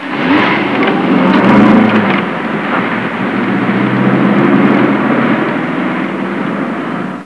motora rūkoņa , jo aiz tās jūtams tāles un ceļa vilinājums.
motors.au